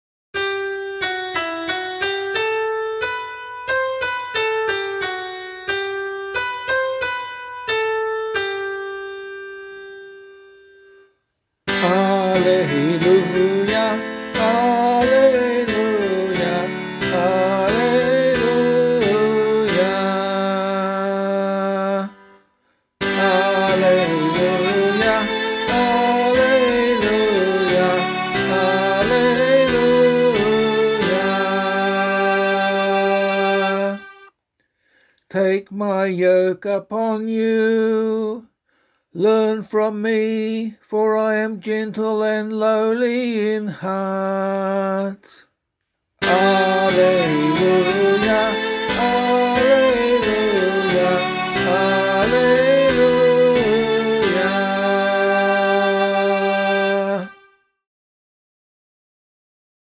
Gospel Acclamation for Australian Catholic liturgy.
035 Sacred Heart Gospel A [LiturgyShare E - Oz] - vocal.mp3